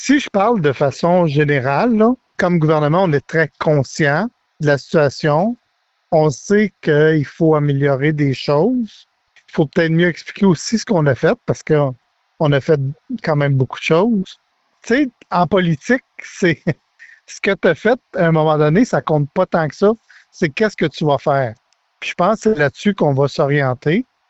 Le député de Nicolet-Bécancour a laissé savoir que son parti a du travail à faire.